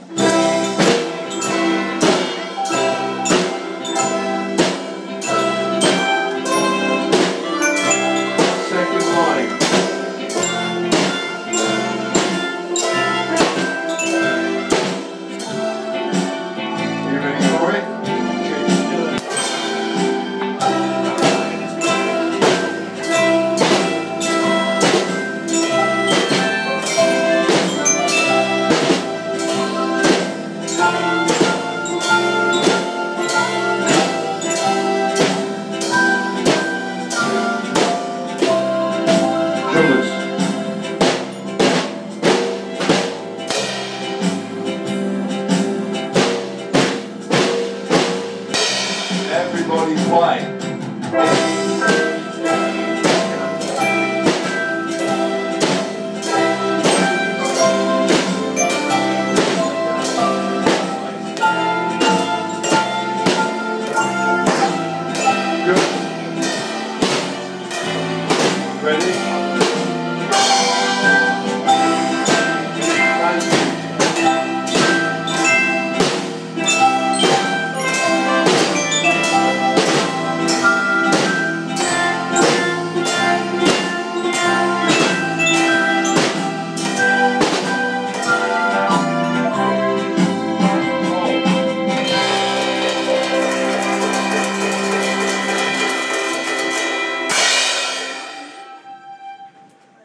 (Y5) 'Jingle Bells' Rock-It Band